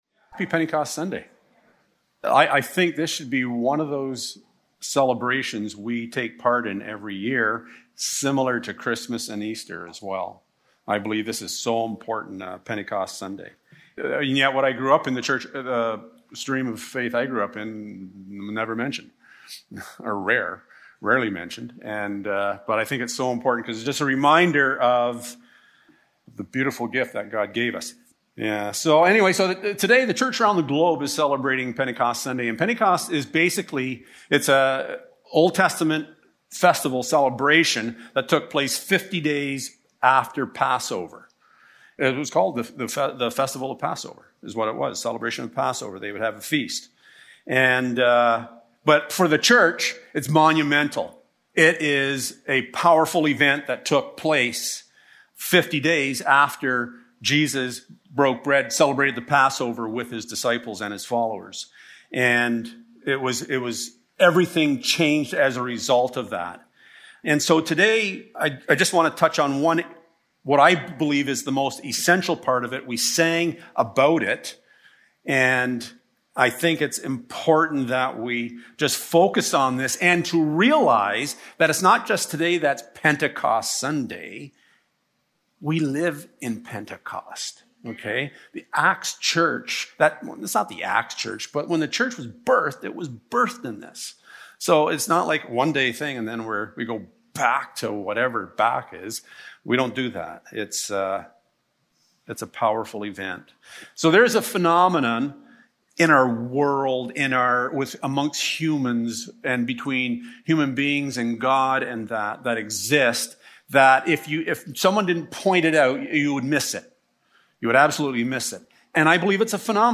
Passage: John 14: 15-18 Service Type: Sunday Morning Topics